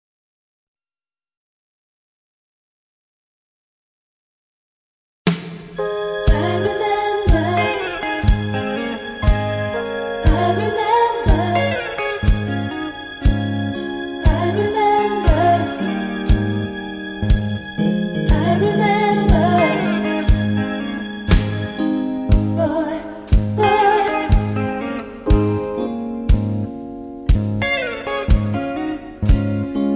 karaoke, mp3 backing tracks
rock and roll, r and b, rock, backing tracks